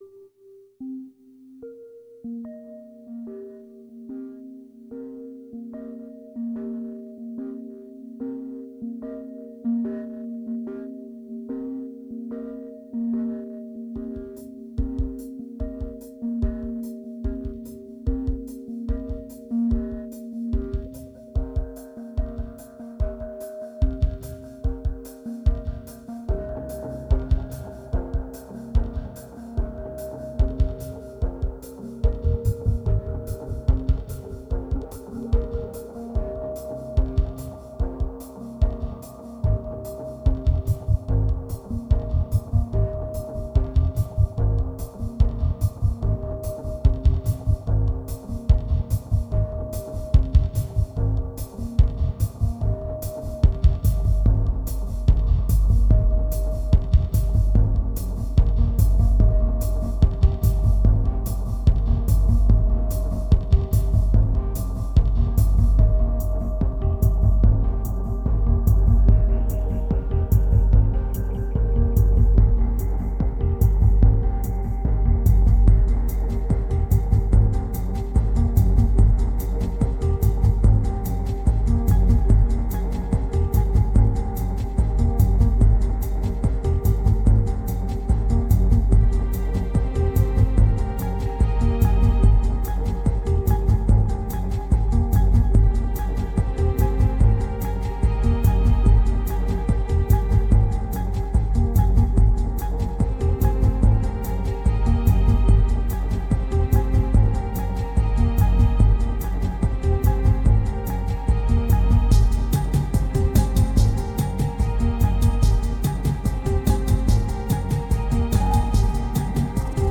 winter moods recorded in Paris
2119📈 - -51%🤔 - 73BPM🔊 - 2010-12-04📅 - -330🌟